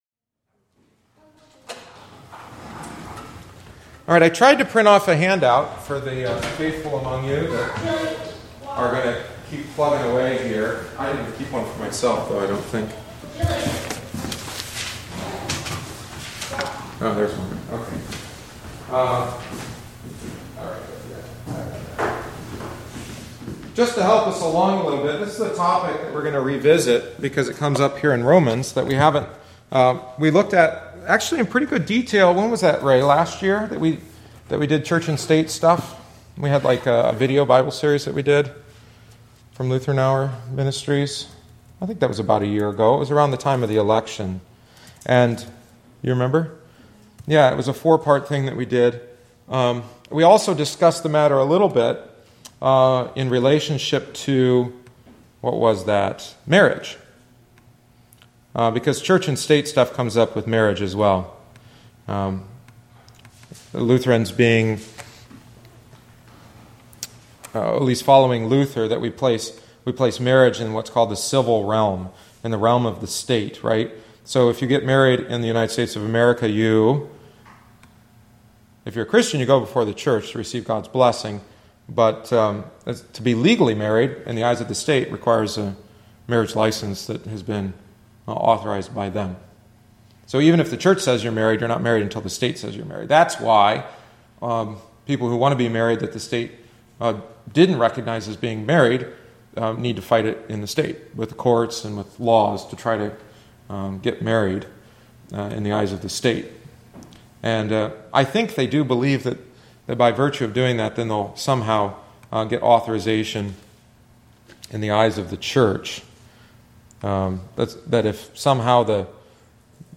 The following is the thirty-first week’s lesson. St. Paul encourages Christians to honor and obey the governmental authorities.